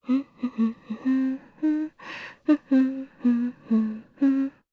Aselica-Vox_Hum_jp.wav